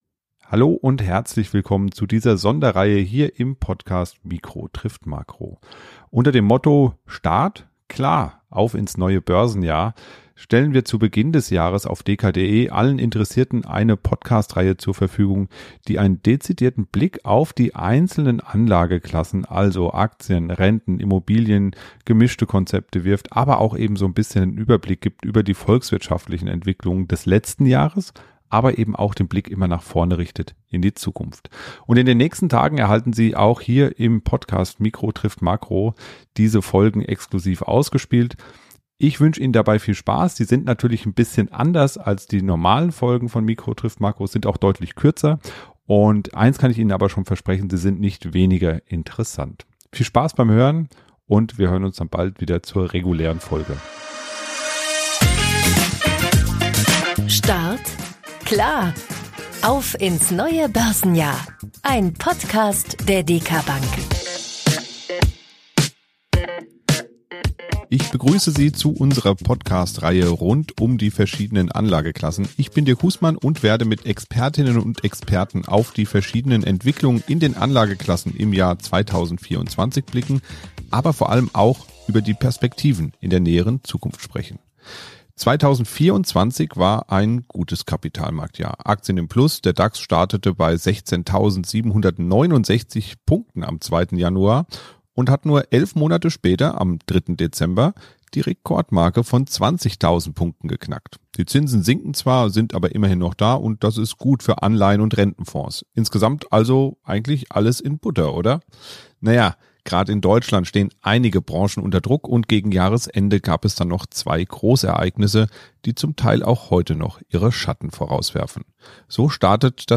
Finanzmarktgespräch